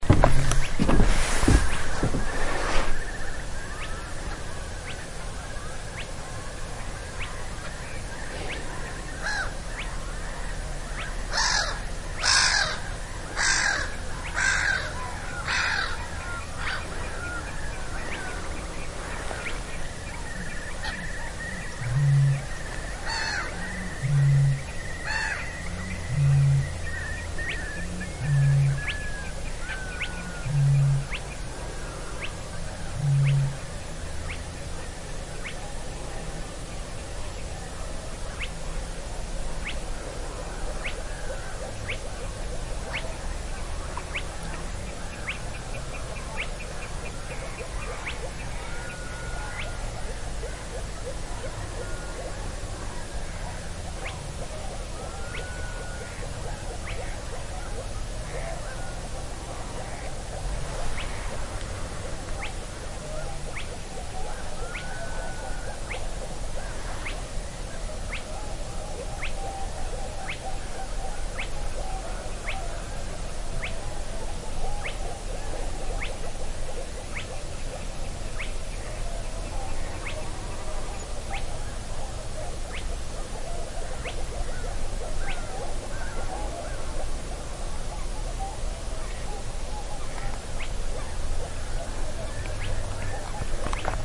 雨天繁忙的郊区街道在匆忙中第二小时
描述：在晚上高峰时段，从一条主要的双向四车道公路上停放了一辆小型车的敞开窗户。任何一个方向都有停车灯，导致交通定期来来往往。在街道外面和车顶和挡风玻璃上可以听到雨声。